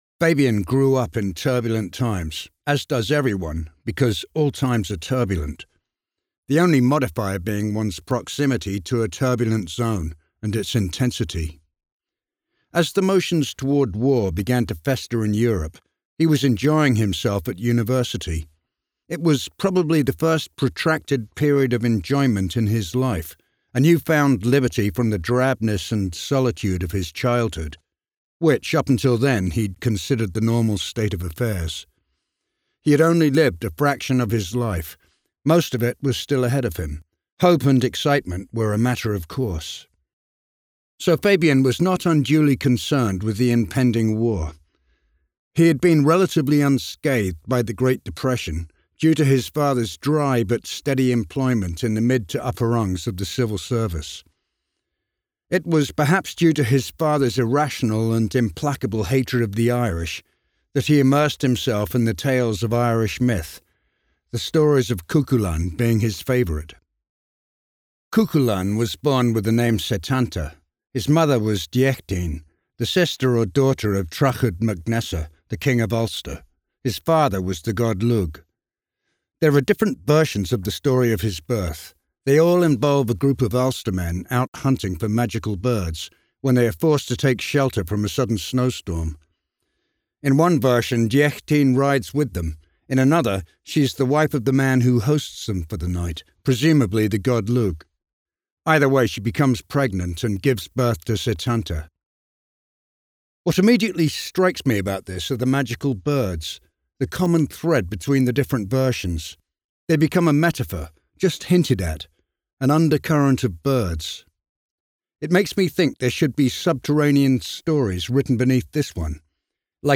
Fabian: A Cubist Biography is available as an audio book